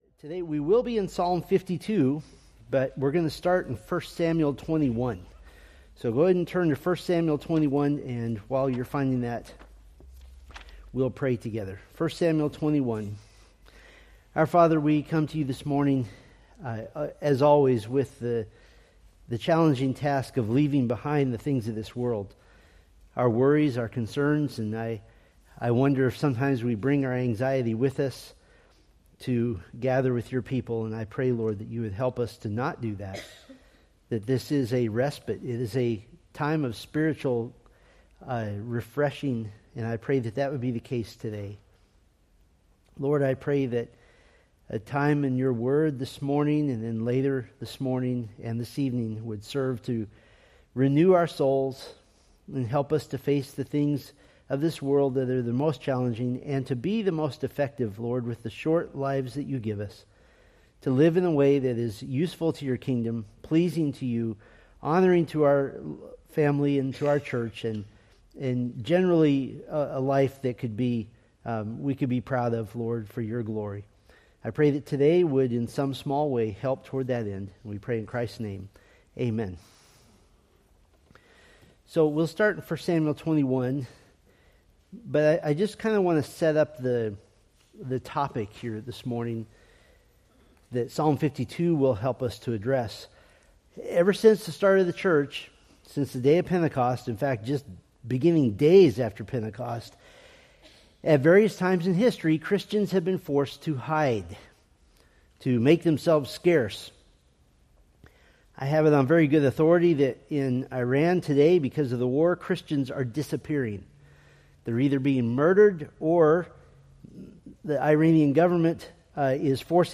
Date: Mar 22, 2026 Series: Psalms Grouping: Sunday School (Adult) More: Download MP3 | YouTube